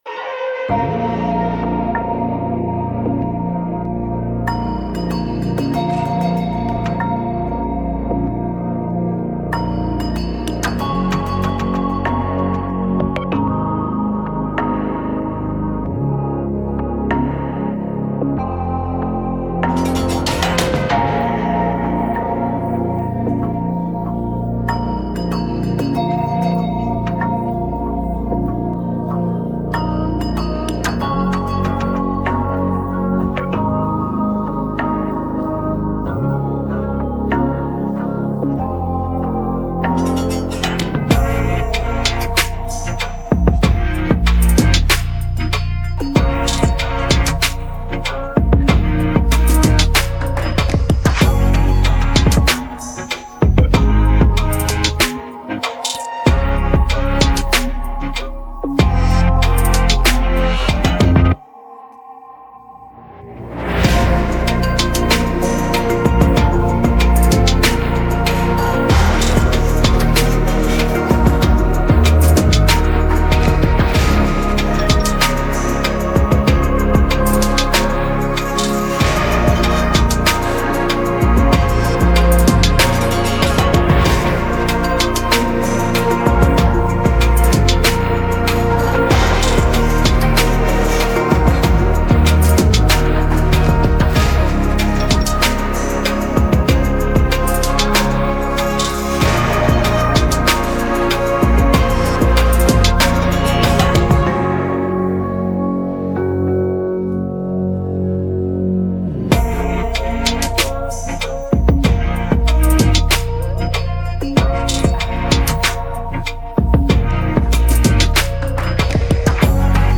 Качественная минусовка